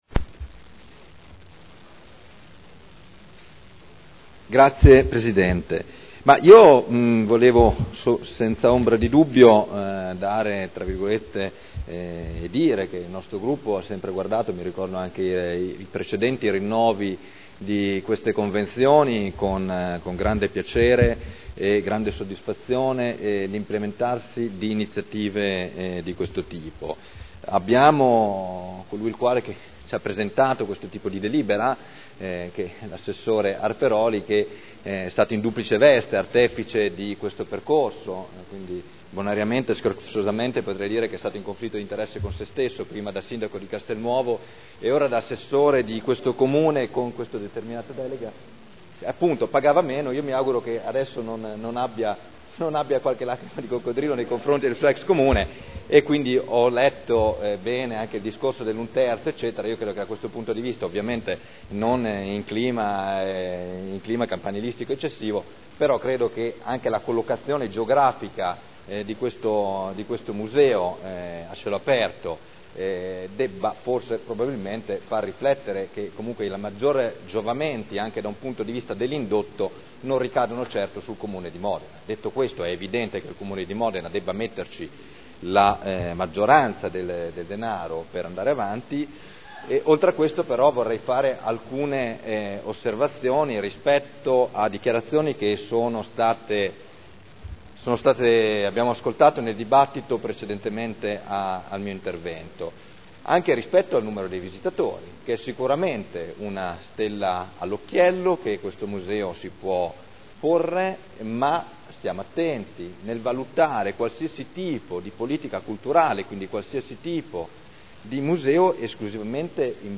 Seduta del 28/04/2011.